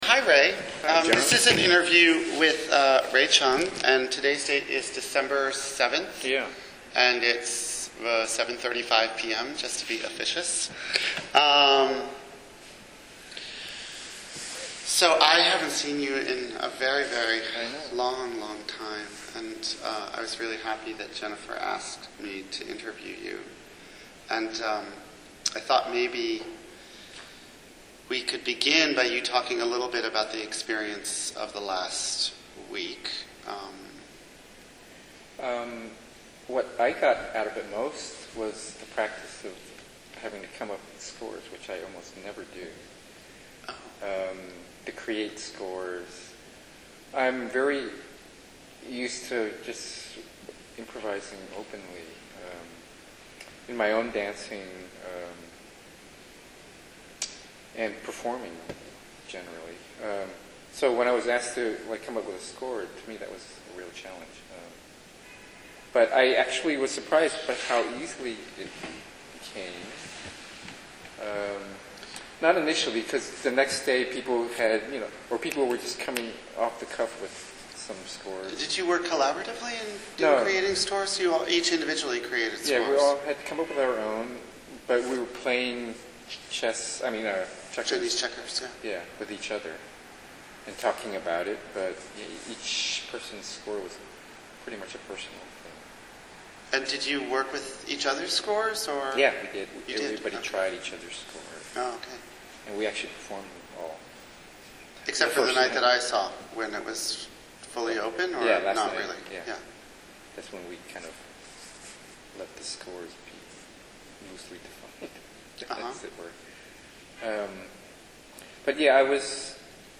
During it, some in-town artists sat with the out-of-town artists to talk about each others work. The conversations were loosely framed around the theme of improvisation. This conversation is about 45 minutes long, and covers topics like the relevance of an audience being in the known about a performance being improvisational, Contact Improvisation as a tool and whether there is a generational stamp on that technique, and the relevance of place and cultural context in the current lives of dance and dance improvisation in different areas of the U.S., Europe and other places.